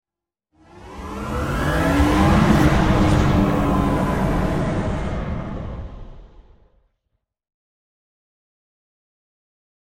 دانلود صدای باد 80 از ساعد نیوز با لینک مستقیم و کیفیت بالا
جلوه های صوتی